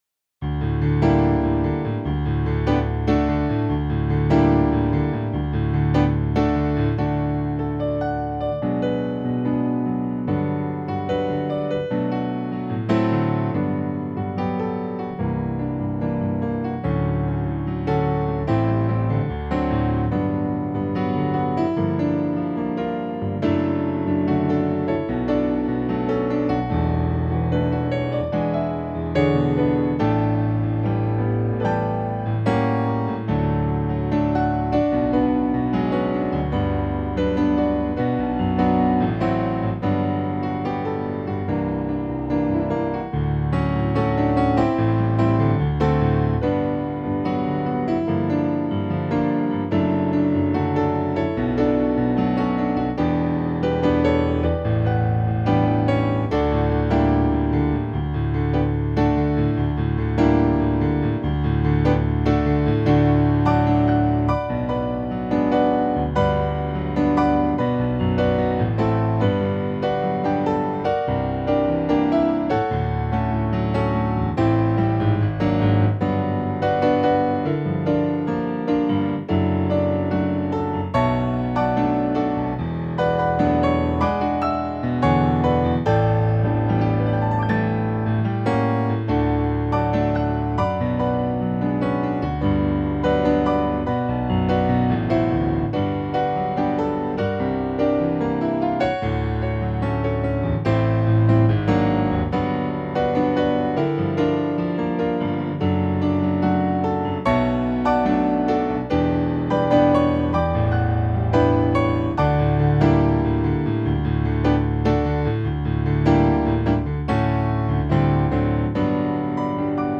Вот, как то делали переложение песенки для ф-но.
Разумеется, это тупо нотки, в Кубасе озвученные.